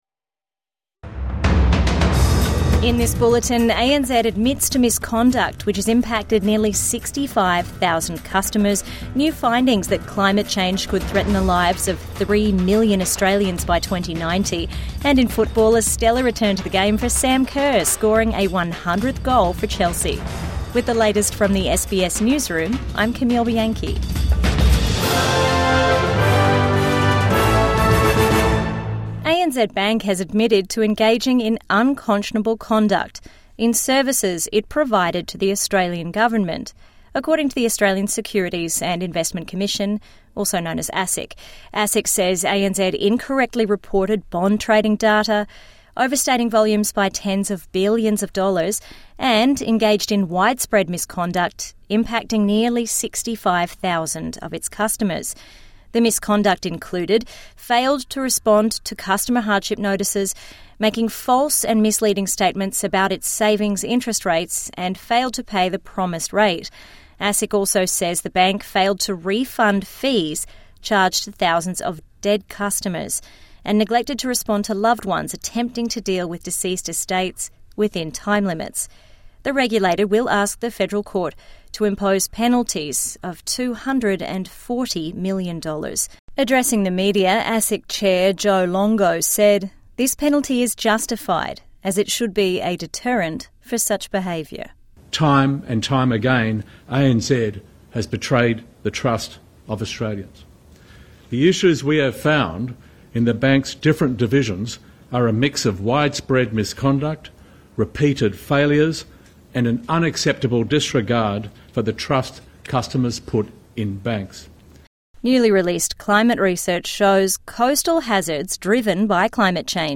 "widespread misconduct" ANZ hit with major fine | Midday News Bulletin 15 September 2025